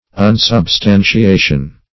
Meaning of unsubstantiation. unsubstantiation synonyms, pronunciation, spelling and more from Free Dictionary.
Search Result for " unsubstantiation" : The Collaborative International Dictionary of English v.0.48: Unsubstantiation \Un`sub*stan`ti*a"tion\, n. [1st pref. un- + substantiation.]
unsubstantiation.mp3